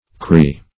cree - definition of cree - synonyms, pronunciation, spelling from Free Dictionary
Crees \Crees\ (kr[=e]z), n. pl.; sing. Cree. (Ethnol.)